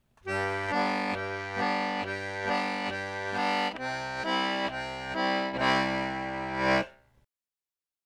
The most common rhythm figure for a two-step is something like, bass-chord-bass-chord, etc.
I’d hazard to say that you’re playing quarter notes when you do this.